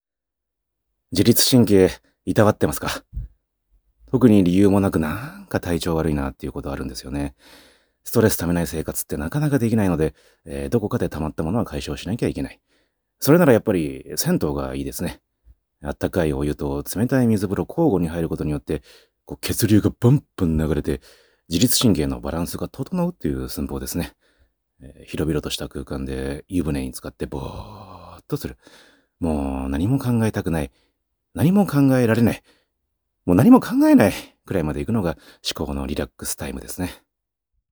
フリートーク